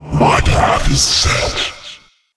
星际争霸音效-protoss-templar-pteyes02.wav